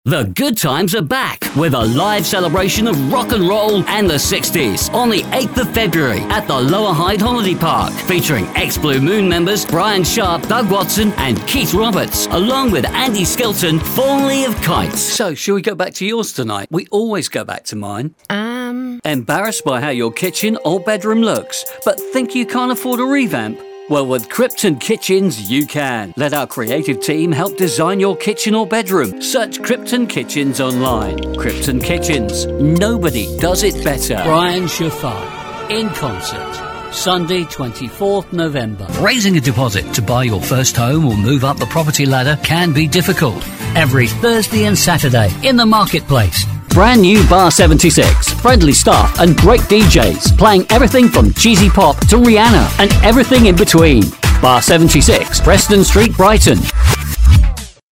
british,voice,over,ISDN,studio,cockney,real, sounding,
cockney
Sprechprobe: Werbung (Muttersprache):